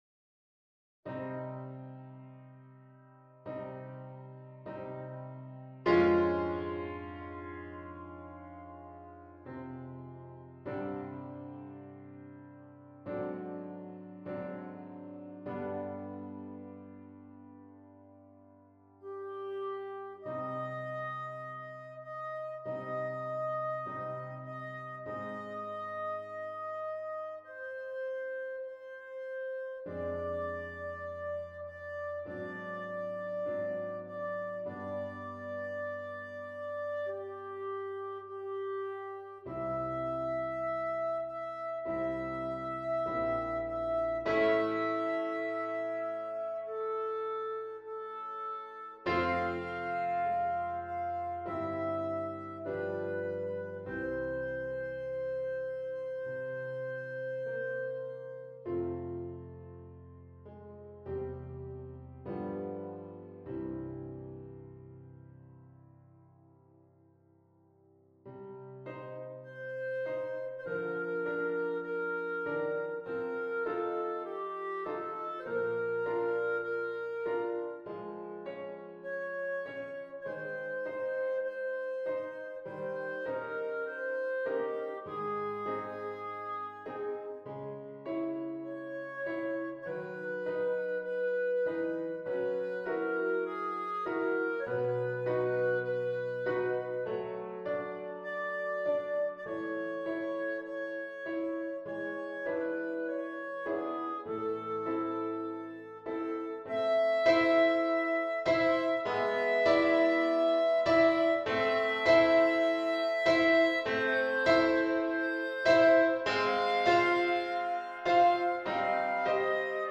This file contains the performance, accompaniment, and sheet music for Bb Clarinet.